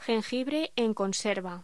Locución: Jengibre en conserva
voz